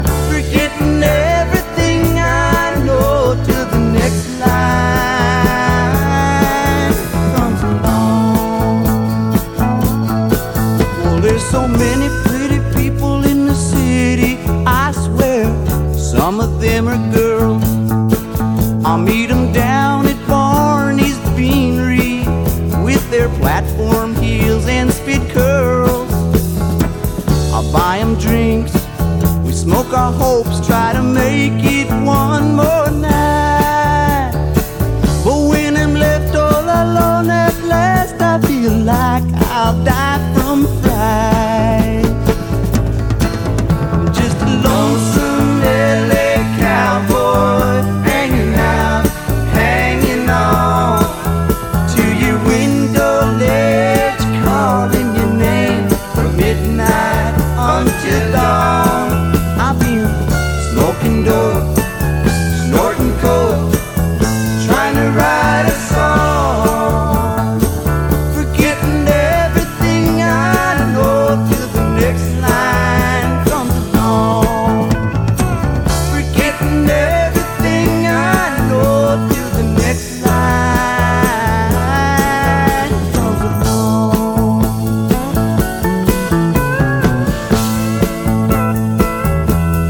ROCK / 70'S / DRUM BREAK
胸キュンのシンセ・ポップ
綺麗なハーモニーで積み上げるパワーポップ